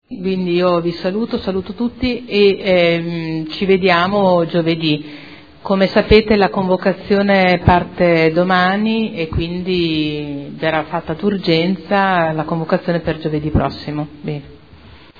Seduta del 03/02/2014. Chiusura lavori